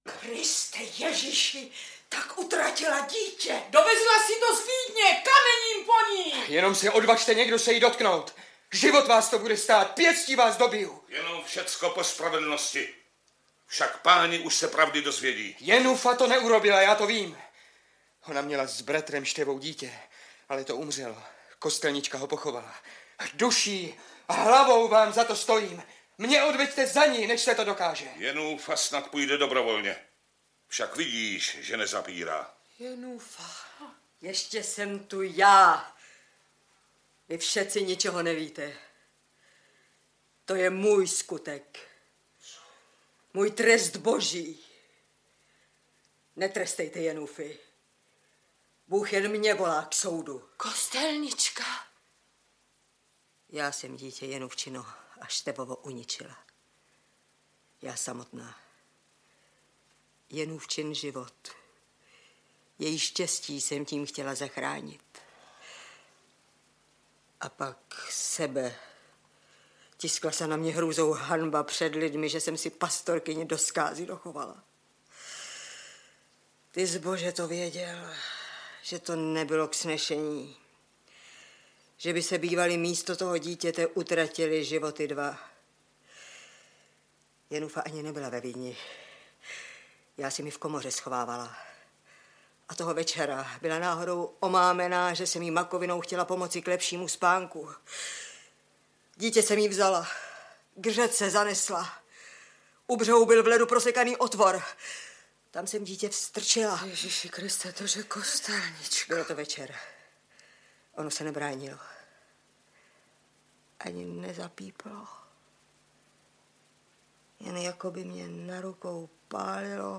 Zasloužilá umělkyně Vlasta Vlasáková - Portrét herečky - Gabriela Preissová - Audiokniha
• Čte: Vlasta Vlasáková, Naděžda Letenská,…